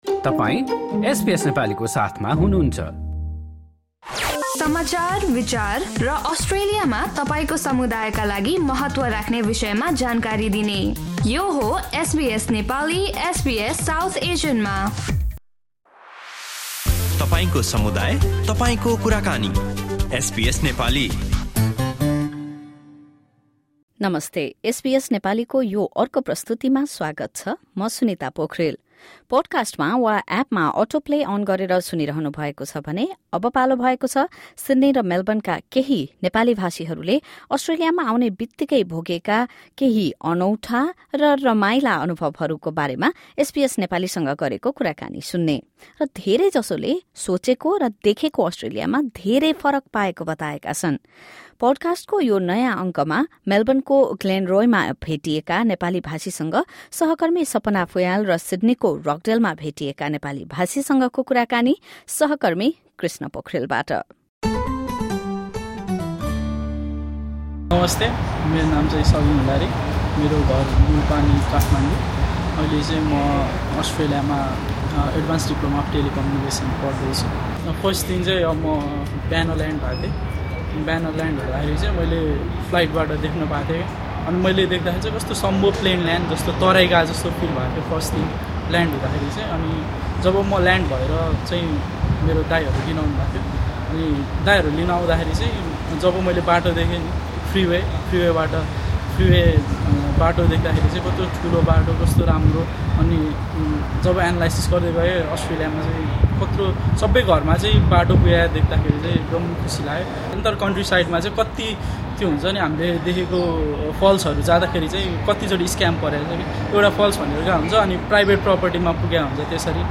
Nepali community members share experiences of their first arrival in Australia.